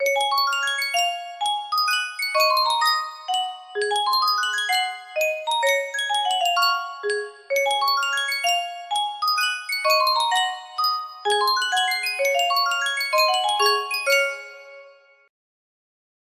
Sankyo Music Box - Maiden's Prayer AO music box melody
Full range 60